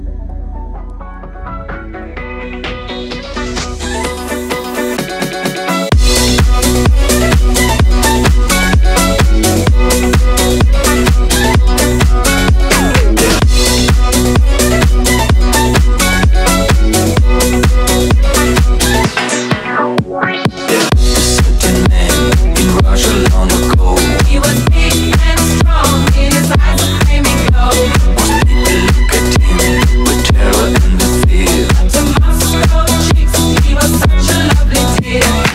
женский вокал
retromix
заводные
Eurodance
Euro House
танцевальные
Зажигательный ремикс